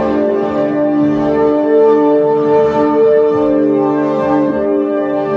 schlechte Aufnahme restaurieren
Ich habe da heute mal rein gehört und sie ist in einem derart schlechtem Zustand, dass ich mich da nicht ran wage.